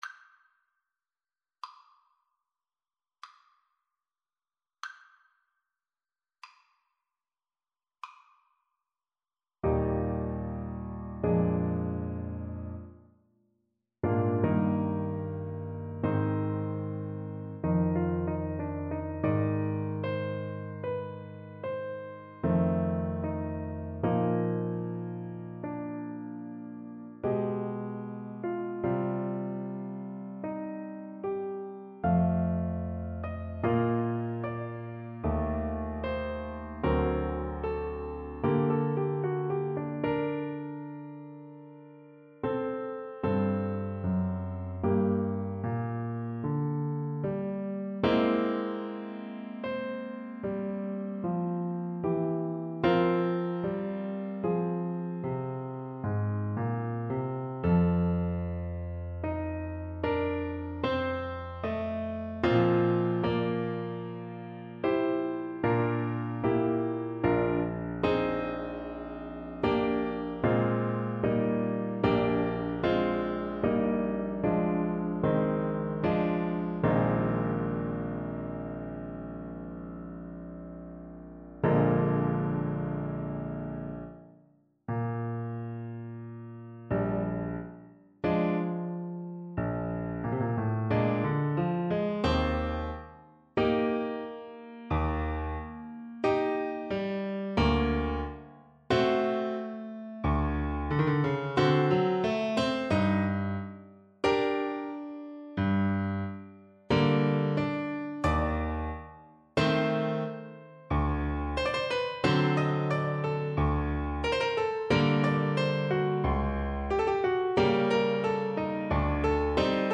Classical Leoncavallo, Ruggero Un tal gioco from Pagliacci Trumpet version
Trumpet
3/4 (View more 3/4 Music)
C5-G6
Eb major (Sounding Pitch) F major (Trumpet in Bb) (View more Eb major Music for Trumpet )
Cantabile (=50) Adagio molto
Classical (View more Classical Trumpet Music)